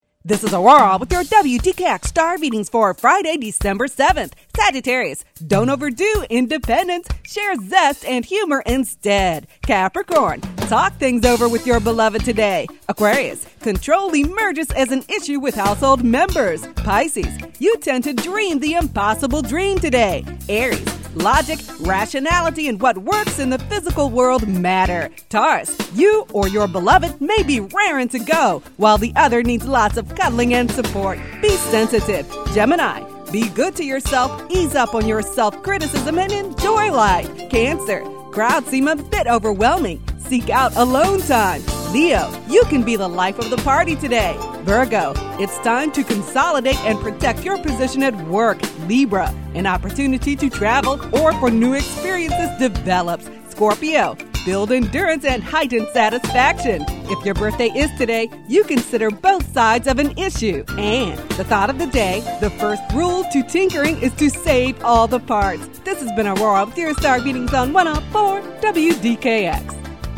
horoscopes.mp3